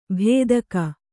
♪ bhēdaka